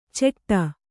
♪ ceṭṭa